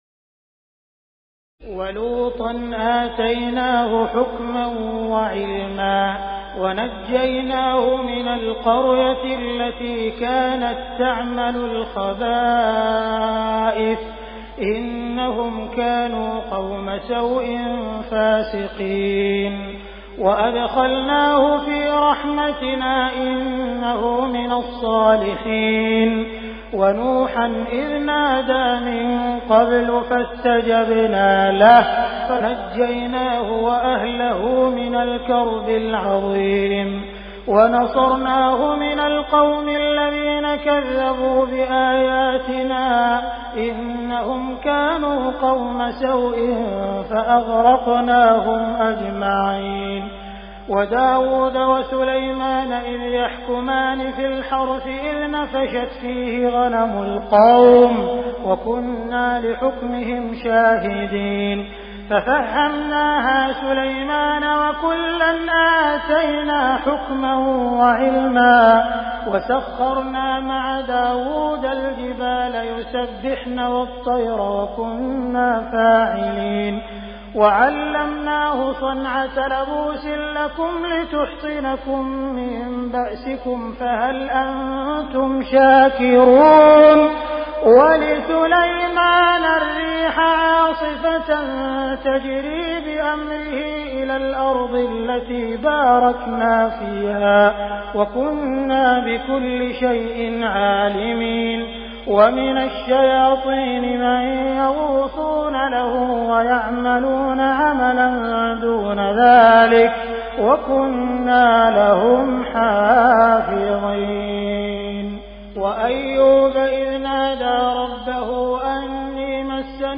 تراويح الليلة السادسة عشر رمضان 1418هـ من سورتي الأنبياء (74-112) و الحج (1-62) Taraweeh 16 st night Ramadan 1418H from Surah Al-Anbiyaa and Al-Hajj > تراويح الحرم المكي عام 1418 🕋 > التراويح - تلاوات الحرمين